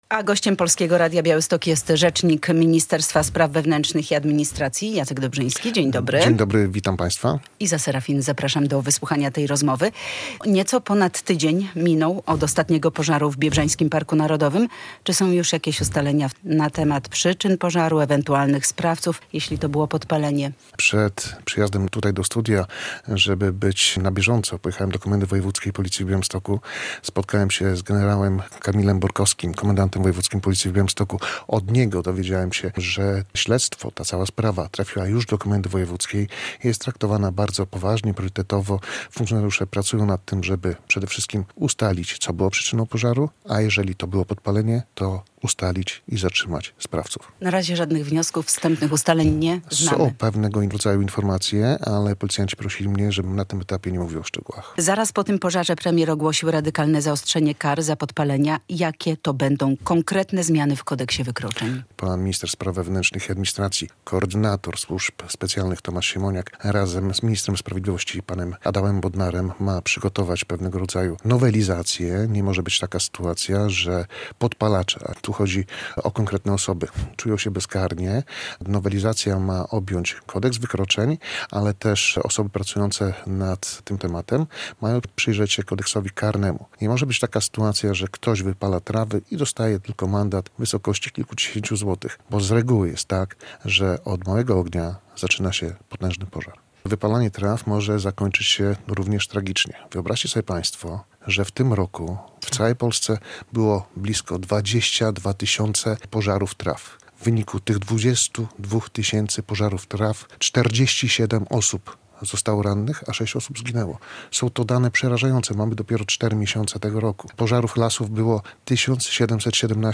Na tym etapie jednak policja nie podaje żadnych szczegółów - mówi gość Polskiego Radia Białystok.